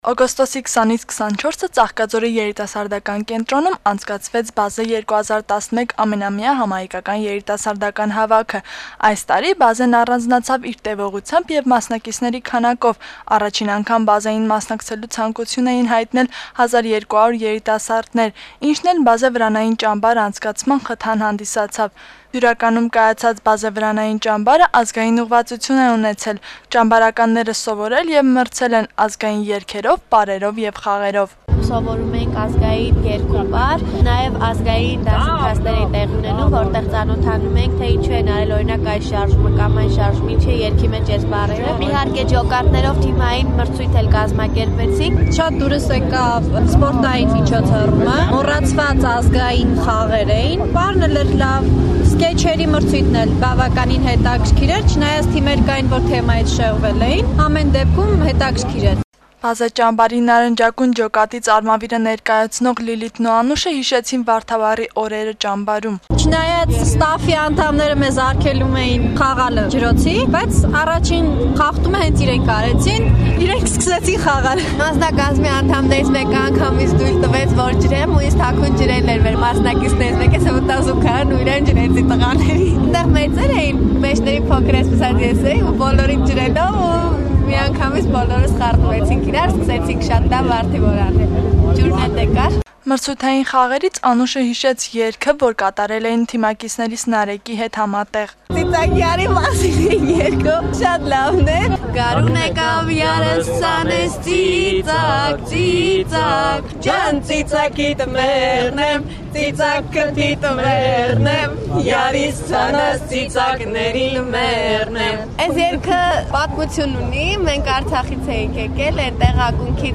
Օգոստոսի 24-ին Ազատության հրապարակում կայացավ «Բազե»-ի փակման հանդիսավոր արարողությունը, որի ընթացքում ճակատների լավագույն 9 մասնակիցները հանդես եկան Առնո Բաբաջանյանի երգերով: